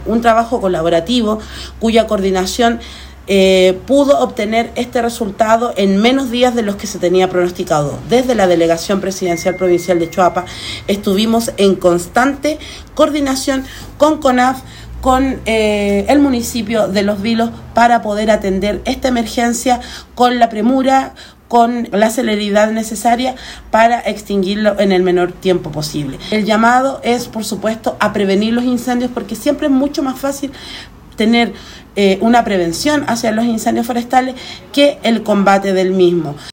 Finalmente, la Delegada Presidencial Provincial de Choapa, Nataly Carvajal Carvajal, destacó